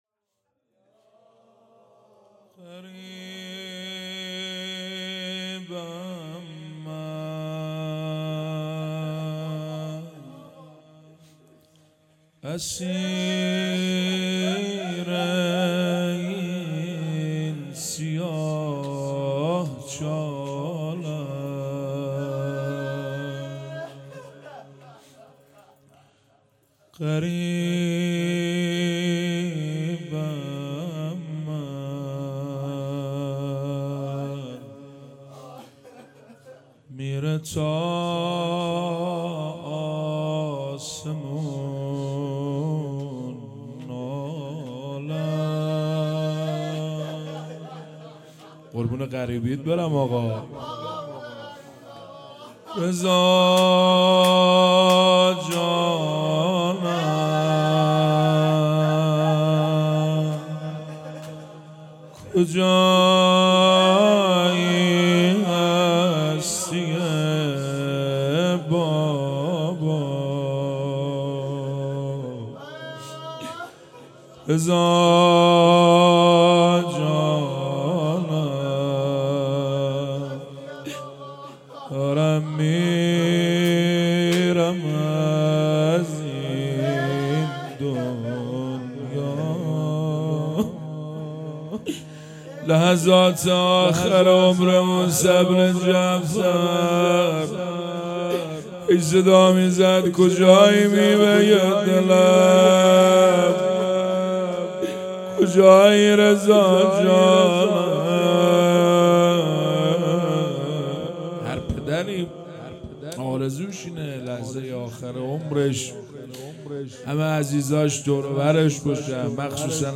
روضه - غریبم من اسیر این سیاه چالم